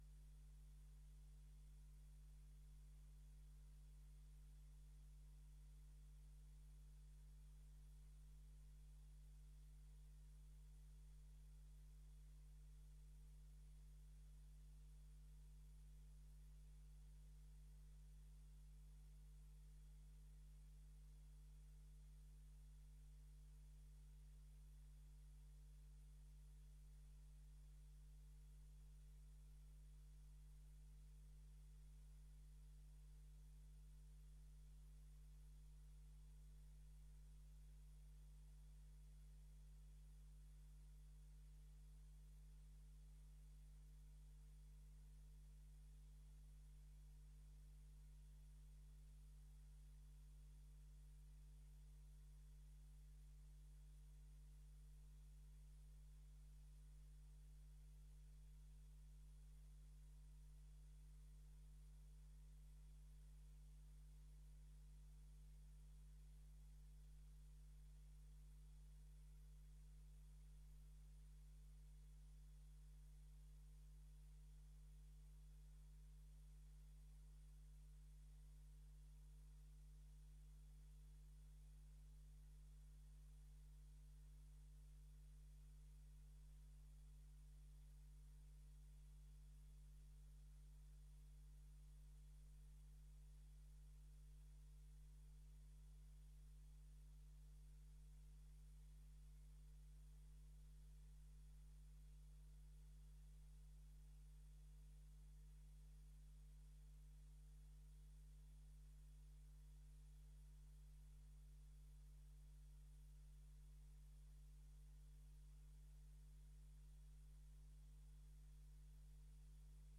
Gemeenteraad 02 december 2024 20:30:00, Gemeente Dalfsen
Locatie: Raadzaal